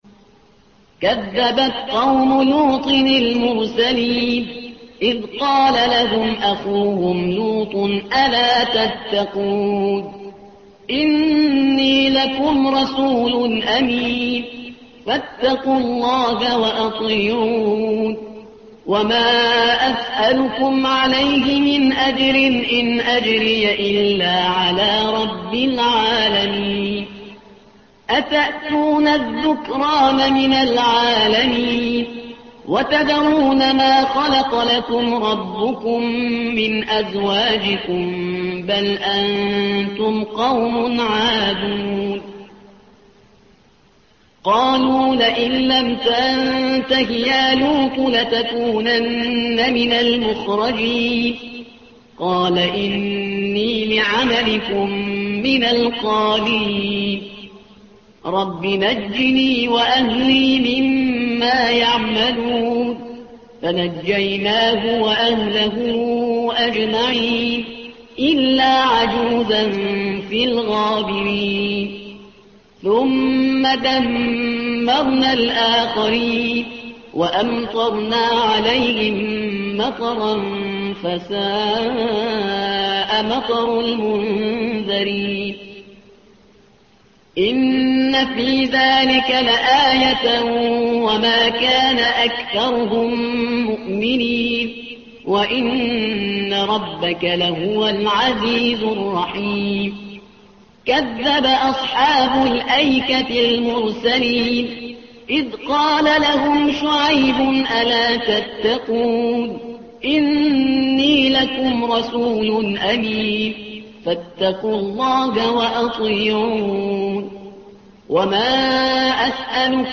تحميل : الصفحة رقم 374 / القارئ شهريار برهيزكار / القرآن الكريم / موقع يا حسين